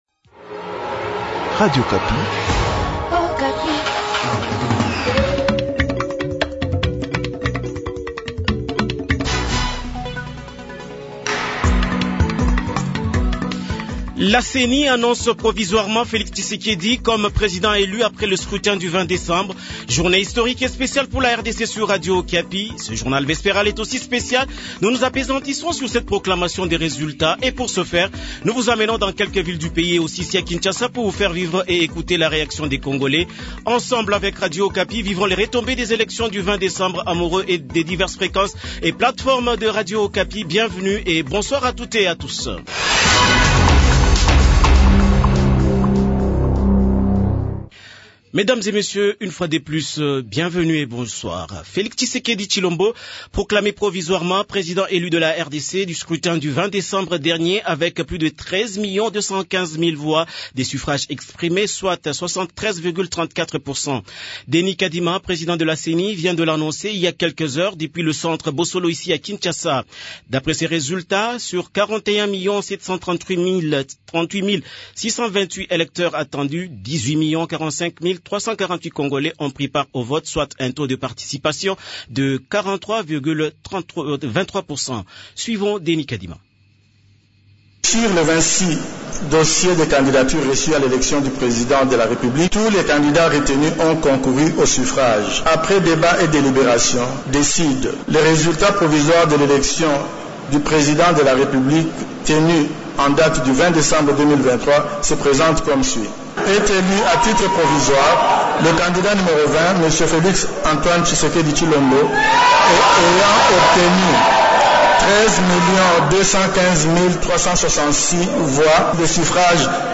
Journal Soir
Kinshasa : Description du contexte et ambiance au centre Bosolo